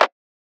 Quarter Milli Clap.wav